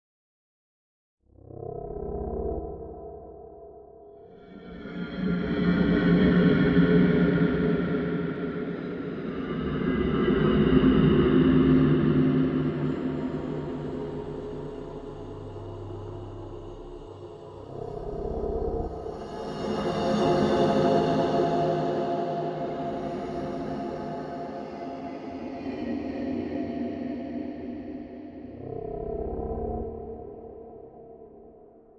Звуки лешего
Эти аудиозаписи передают дух лесного существа из славянской мифологии – от угрожающего рычания до загадочного шороха листьев.
Шепот лешего среди деревьев (таинственный звук)